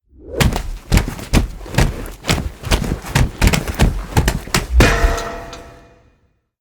anonStairFall.ogg